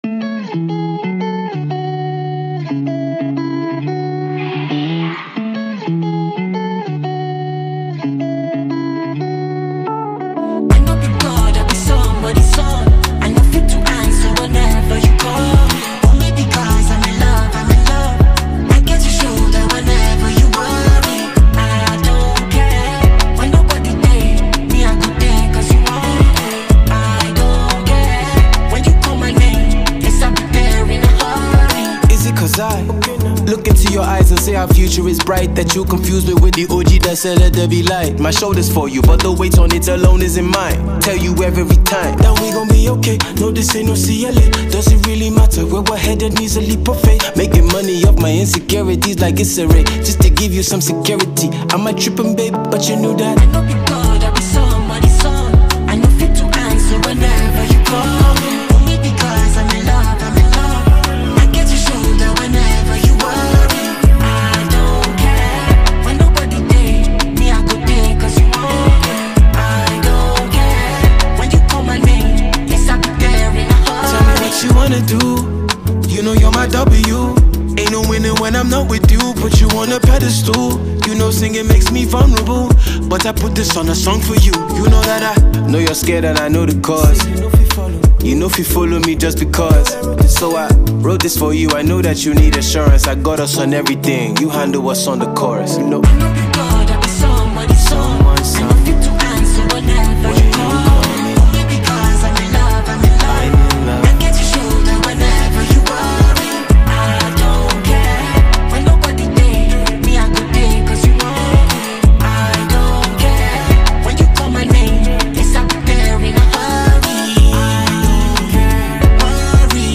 Nigerian talented rapper and songwriter